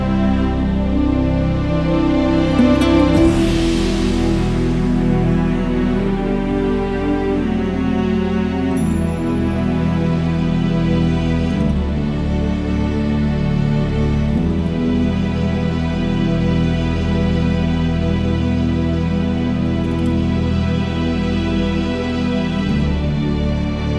No Backing Vocals Easy Listening 4:41 Buy £1.50